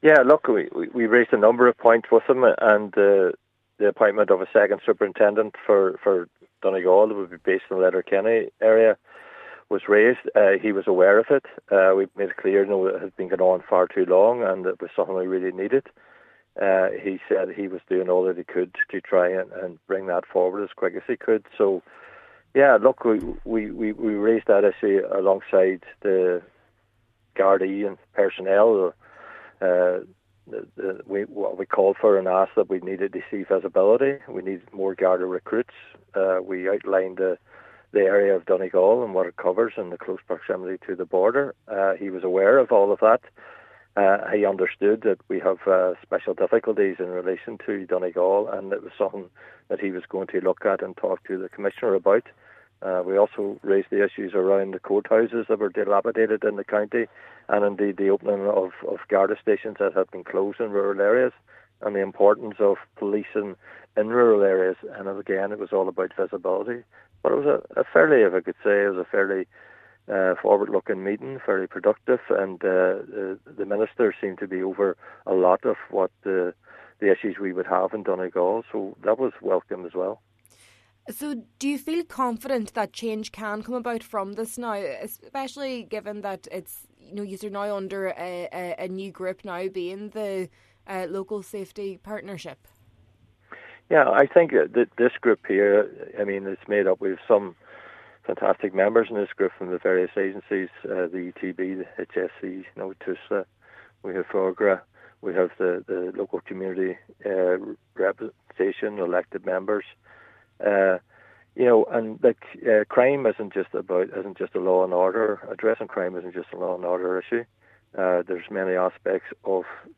Cllr McMonagle added crime prevention is also key: